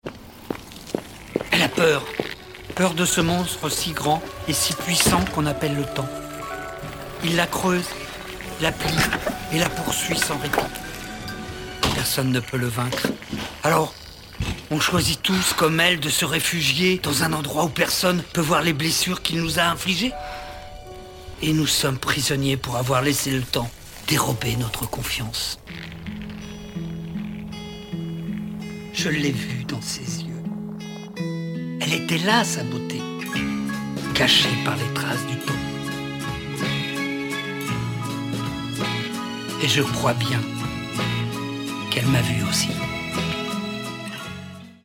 Voix Off - Court-Métrage
36 - 60 ans - Ténor